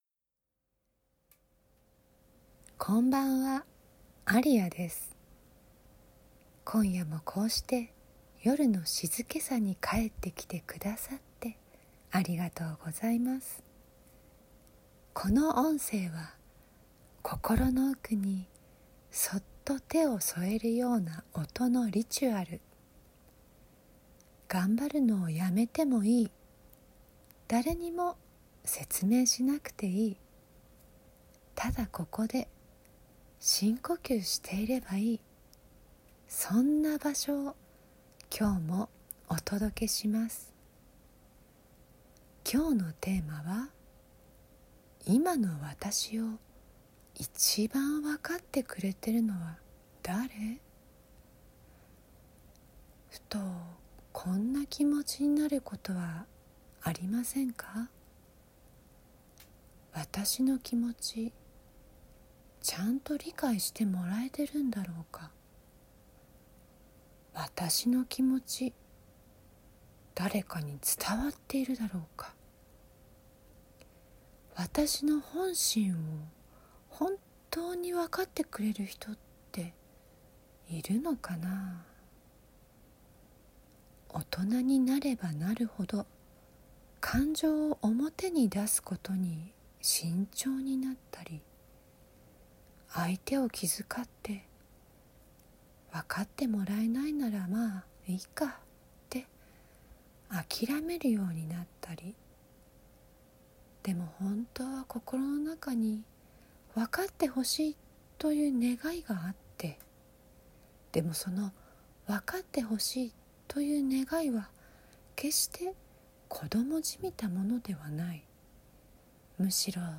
いま、誰に理解されたい？「わたし」を深める航海 Day4 | Whisper from Aria #032 - Whisper from Aria 静けさの声で整える音声リチュアル
Audio Channels: 1 (mono)